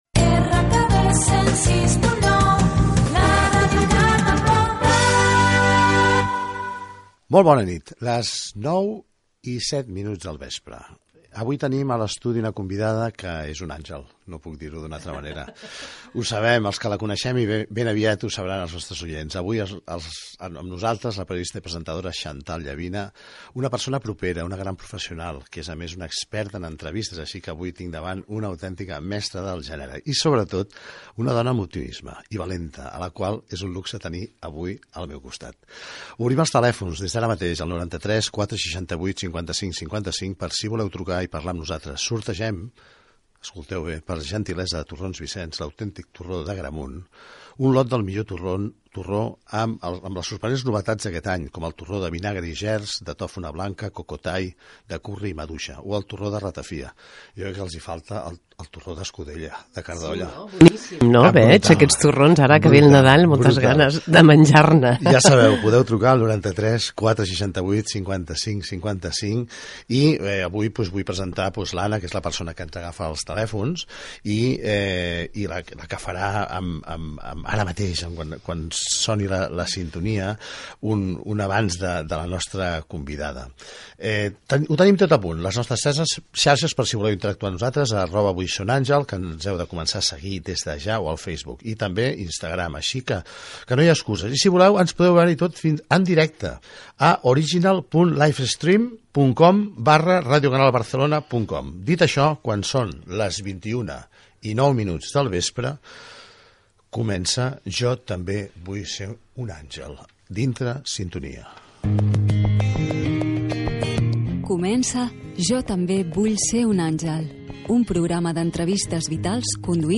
Indicatiu de l'emissora, hora, publicitat, telèfon del programa, formes de seguir-lo, indicatiu del programa, perfil de la invitada Xantal Llavina, publicitat i tema musical
Entreteniment